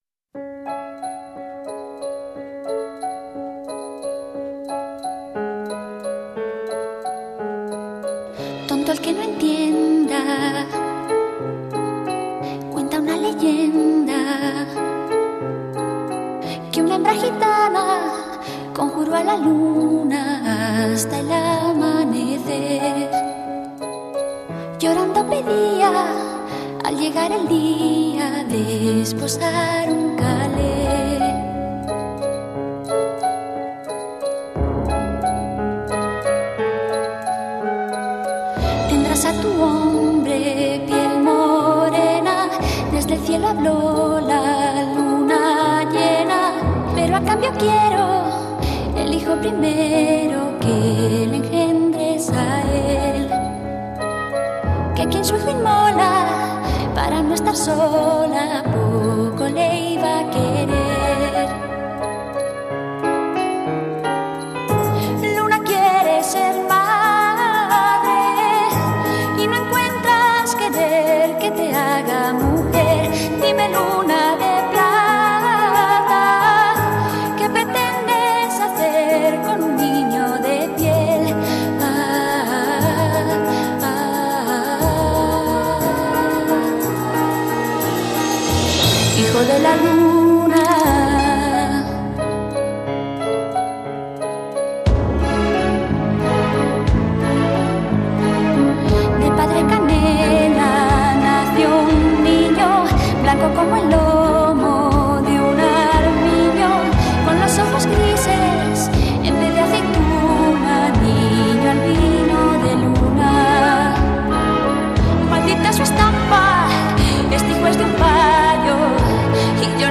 02 Viennese Waltz